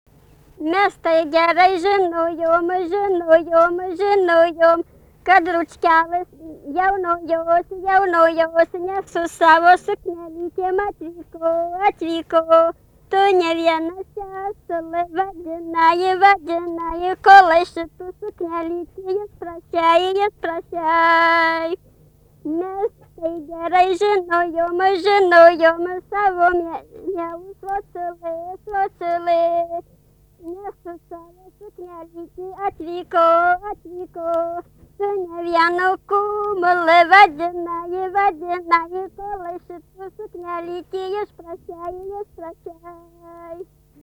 daina, vestuvių
Pakuliškės
vokalinis
Prastas įrašas